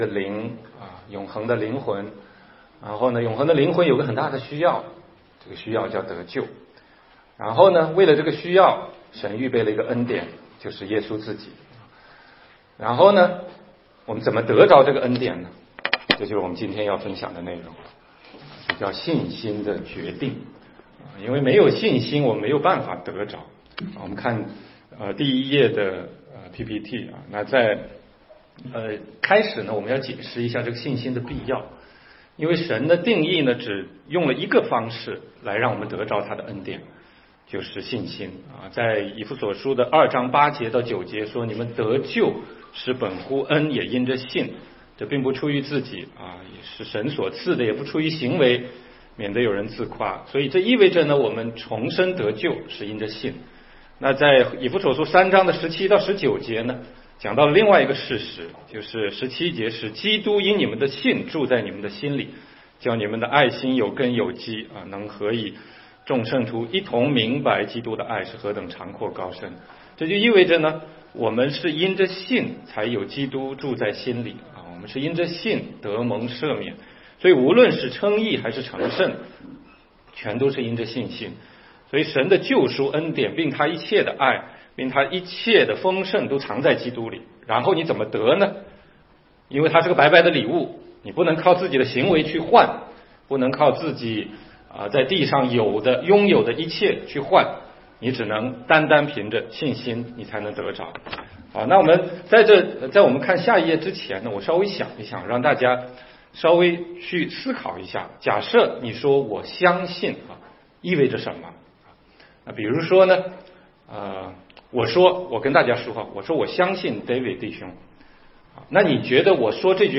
16街讲道录音 - 属灵知识系列之四：信心的决定